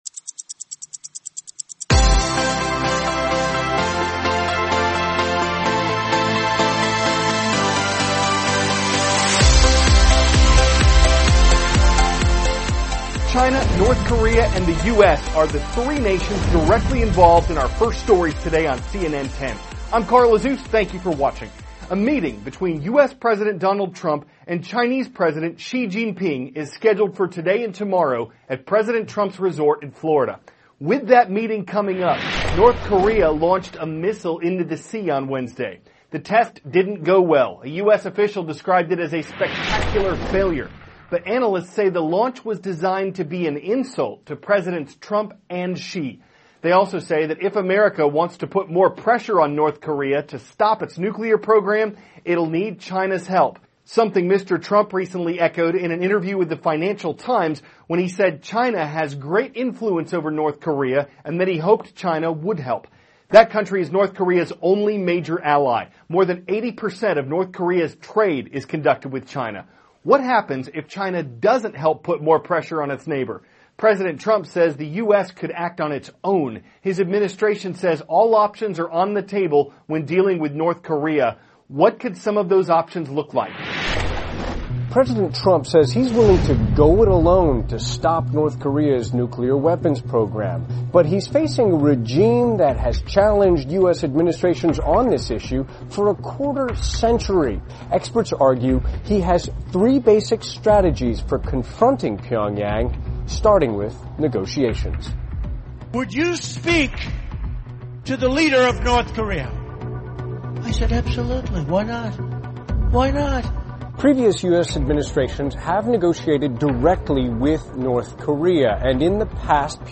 (cnn Student News) -- April 6, 2017 The U.S. and Chinese Leaders Prepare for a Meeting in Florida; A Saturn Mission Nears its End; Health Benefits of Picking Up A Book THIS IS A RUSH TRANSCRIPT.